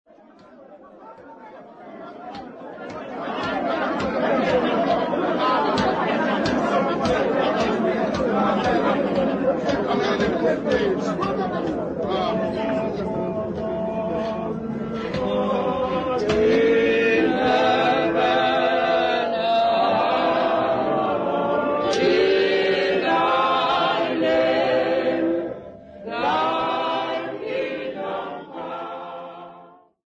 Folk music
Sacred music
Africa South Africa Tsolo, Eastern Cape sa
field recordings
Unaccompanied church hymn